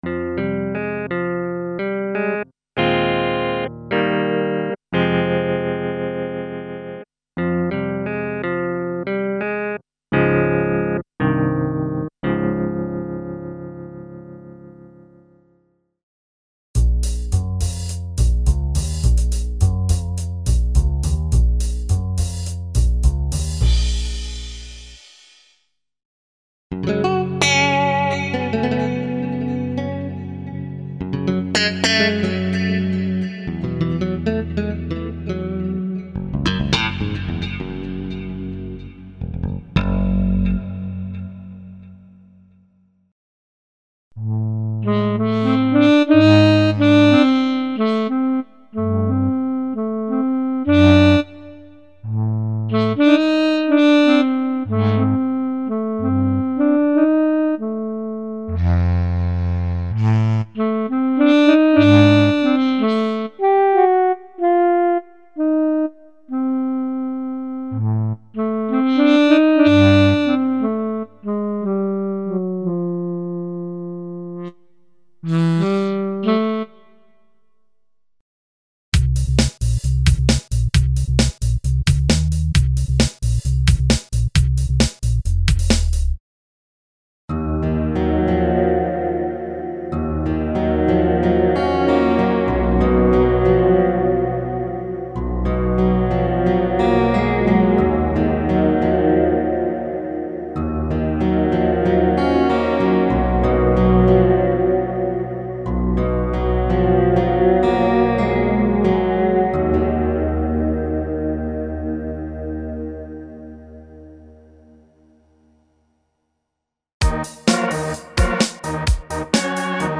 Rock, Jazz and Ethno sound banks (el. clean & dist. guitars, basses, organs, pianos and ethno layers).
Info: All original K:Works sound programs use internal Kurzweil K2500 ROM samples exclusively, there are no external samples used.